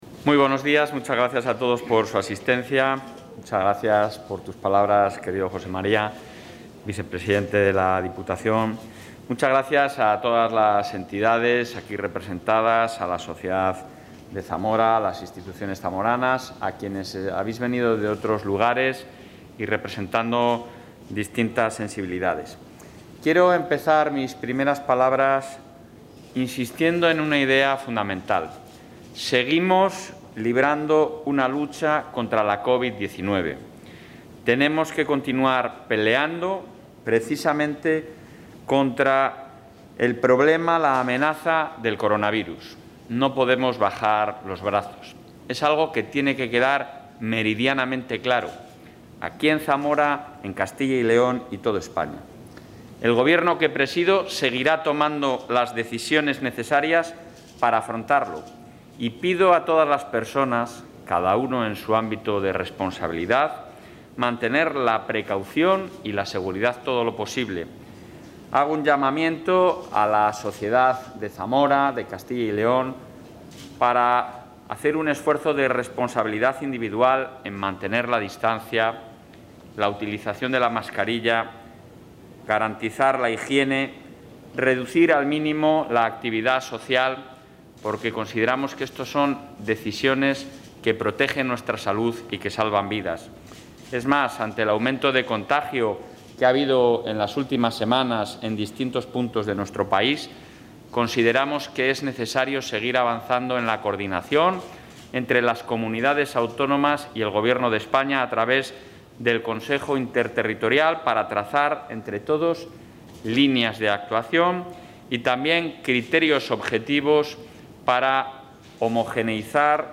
Intervención del presidente.